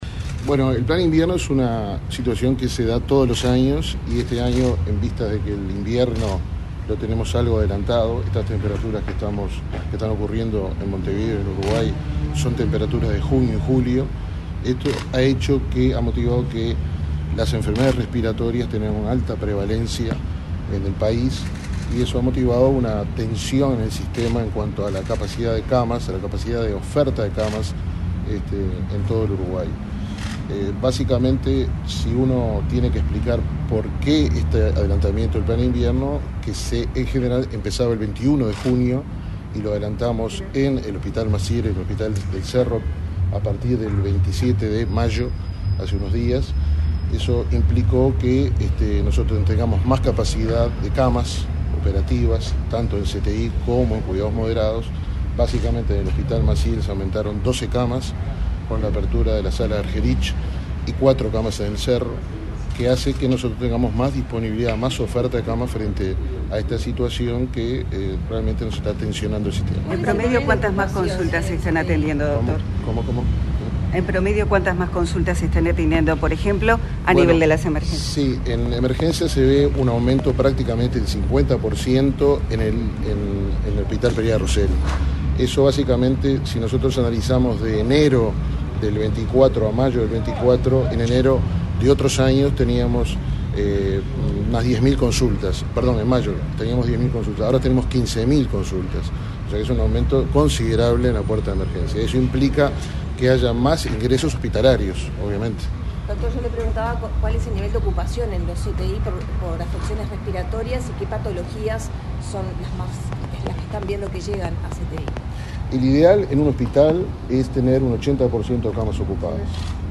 Declaraciones a la prensa del gerente general interino de ASSE, Juan Ramón Blanco
El gerente general interino de la Administración de los Servicios de Salud del Estado (ASSE), Juan Ramón Blanco, realizó declaraciones a medios